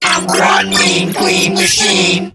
8bitvirus_start_vo_03.ogg